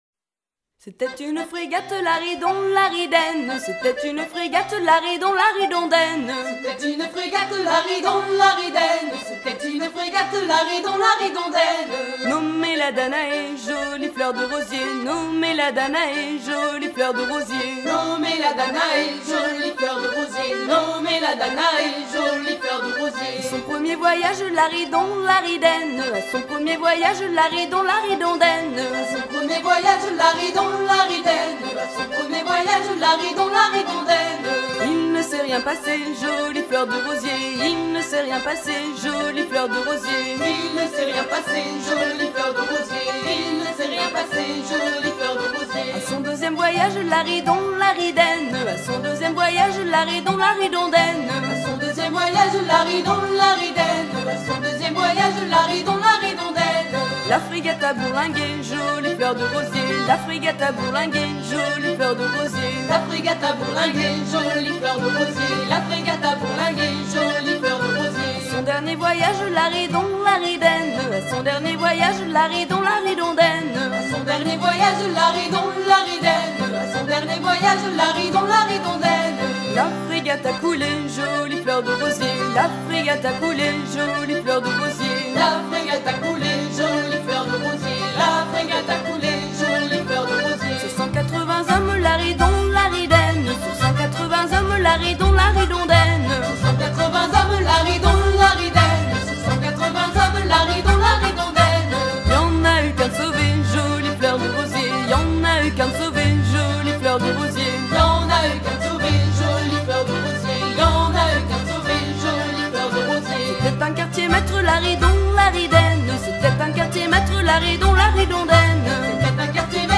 danse : laridé, ridée
Genre laisse
Pièce musicale éditée